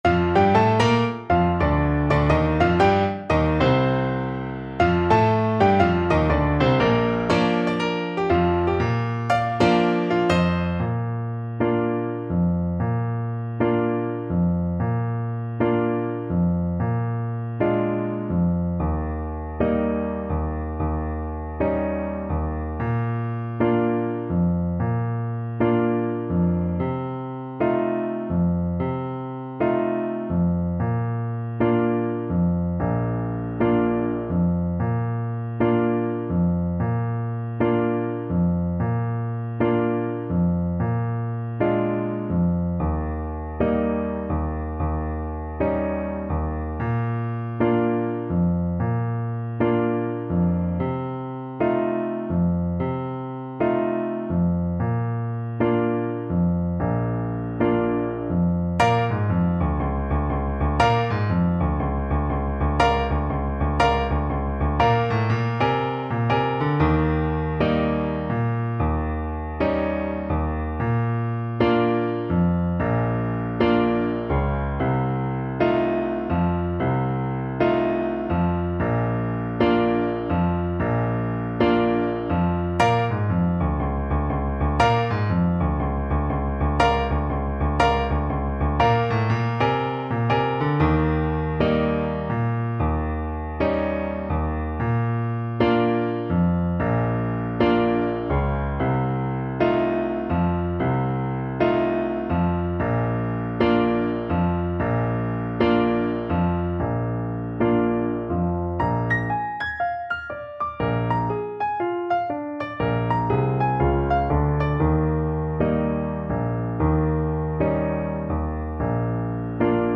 Moderato = 120
Jazz (View more Jazz Trombone Music)